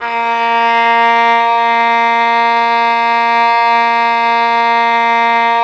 Index of /90_sSampleCDs/Roland L-CD702/VOL-1/STR_Violin 4 nv/STR_Vln4 _ marc
STR VLN BO02.wav